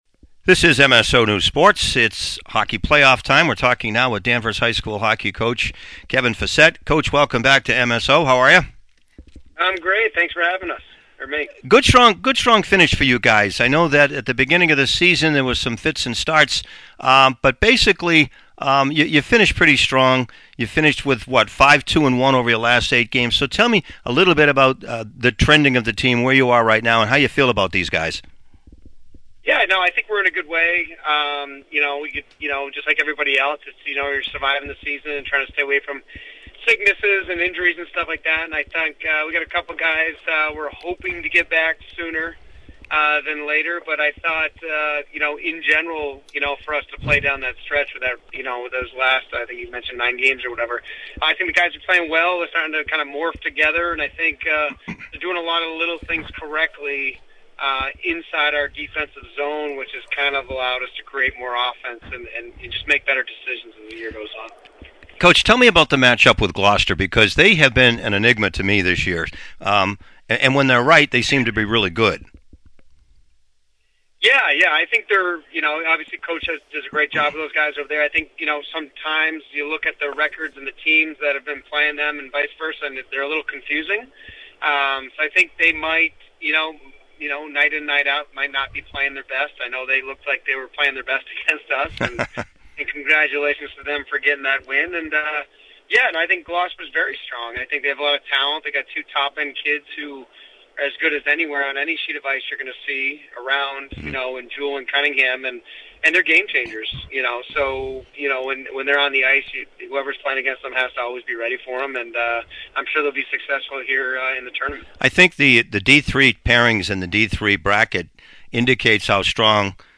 In the interview below, he describes what it will take to come out on top in the tournament opener against 20th-seeded West Springfield (12-8-0) with puck-drop Thursday night, 7:30, at the Bourque Arena.